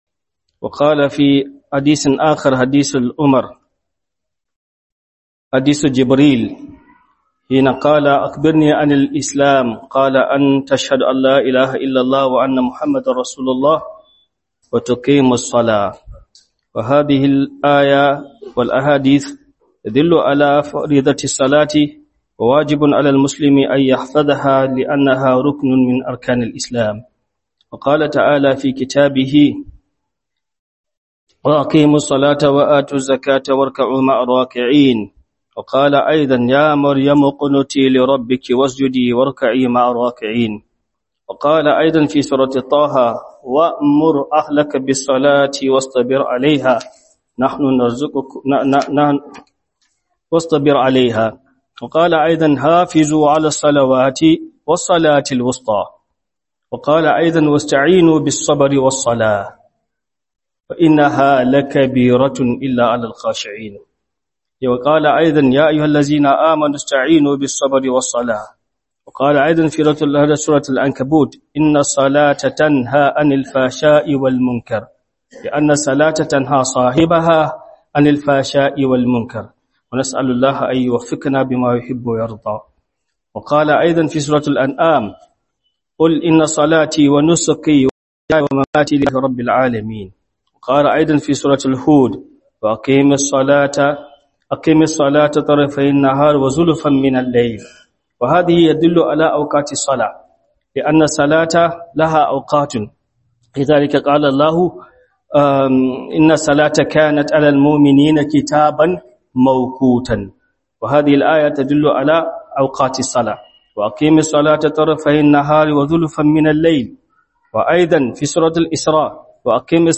Play Radio